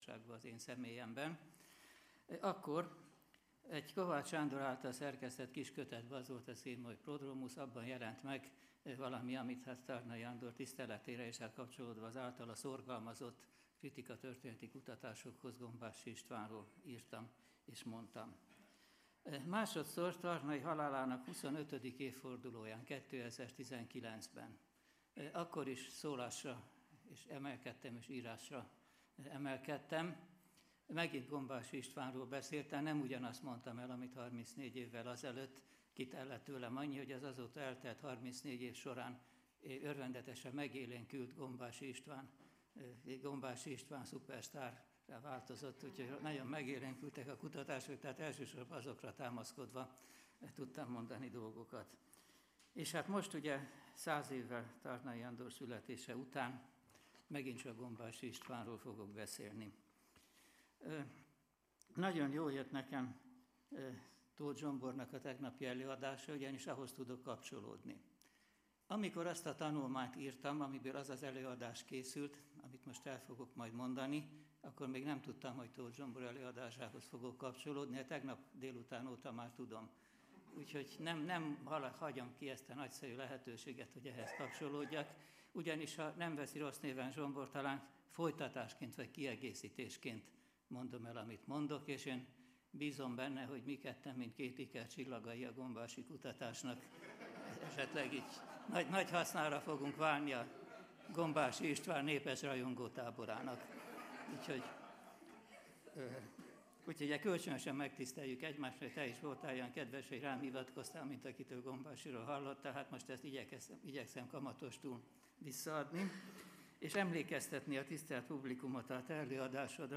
(lecturer)